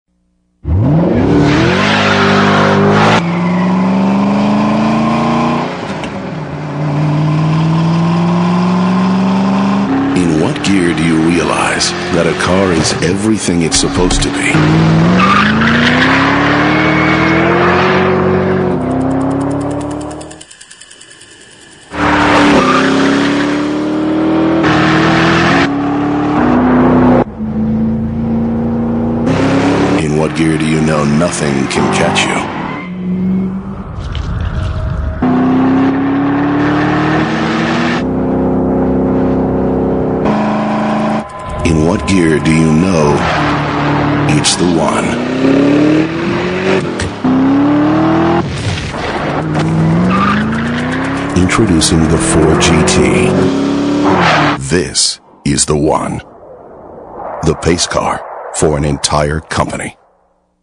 Super Bowl TV Spot Download This Spot